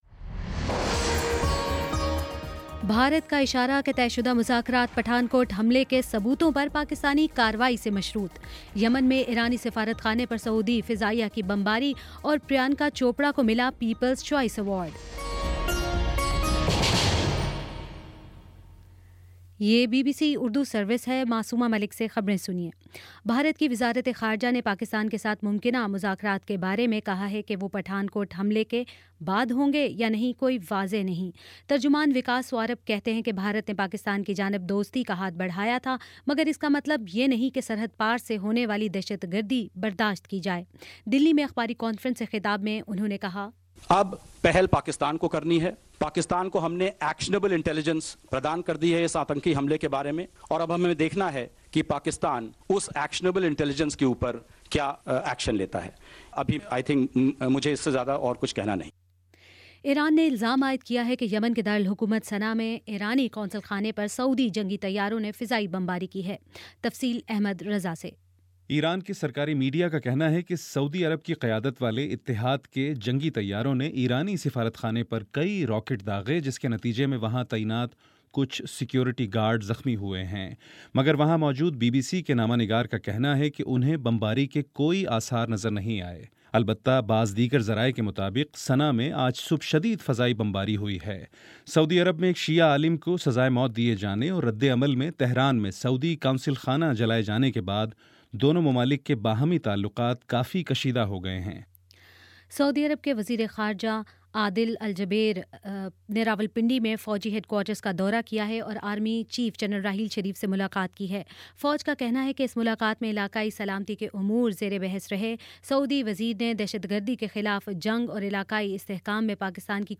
جنوری 07 : شام چھ بجے کا نیوز بُلیٹن